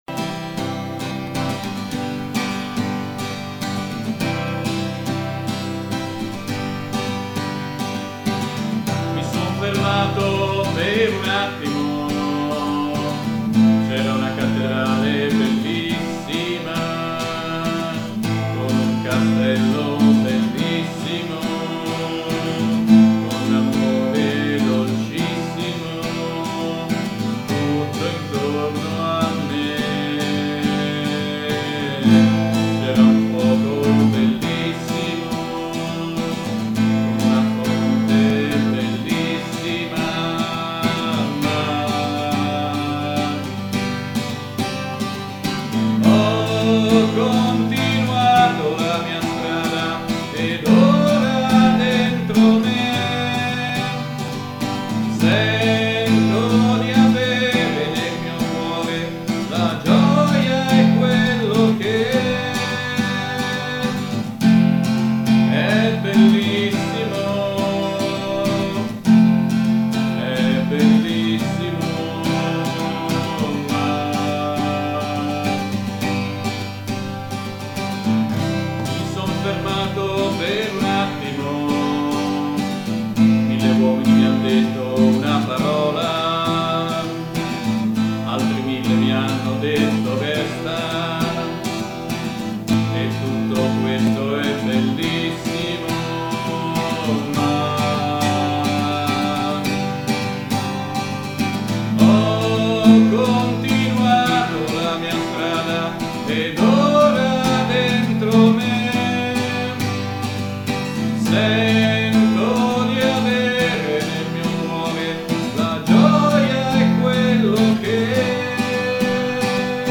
suona la chitarra